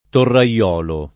torraiolo [ torra L0 lo ]